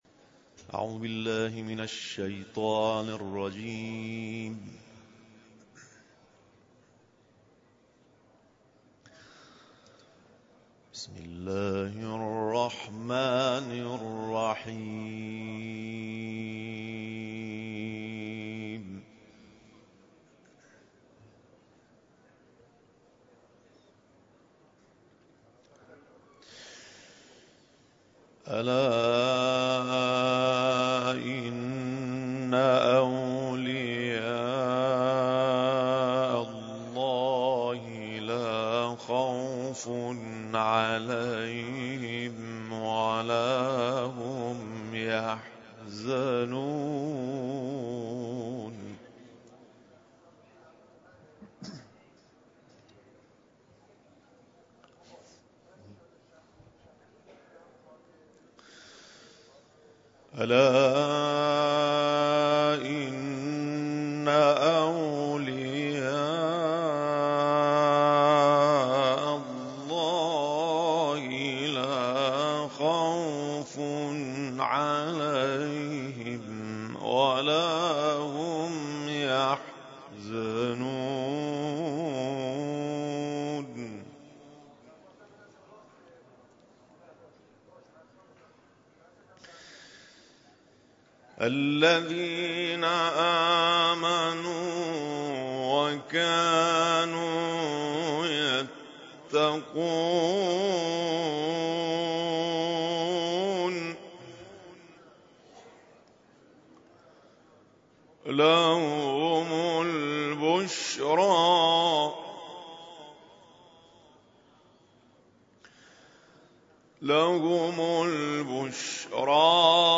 نماز جمعه